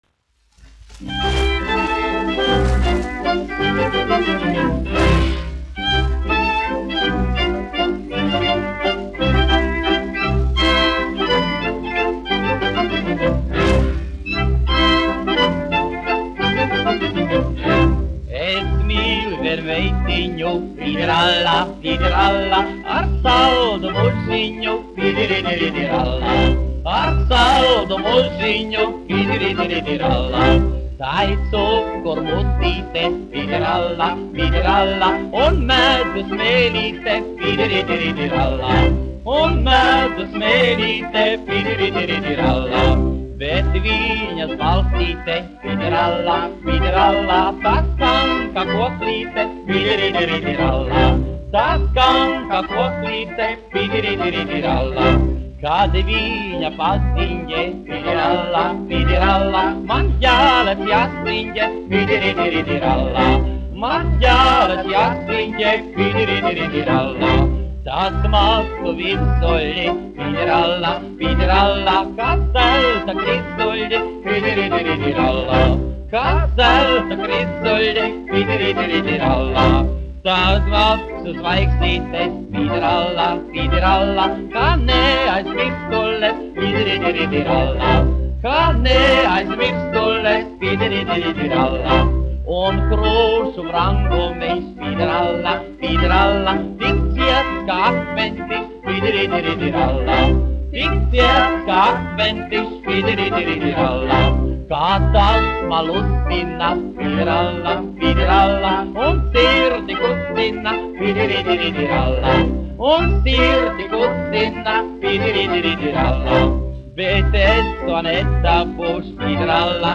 1 skpl. : analogs, 78 apgr/min, mono ; 25 cm
Populārā mūzika
Humoristiskās dziesmas
Latvijas vēsturiskie šellaka skaņuplašu ieraksti (Kolekcija)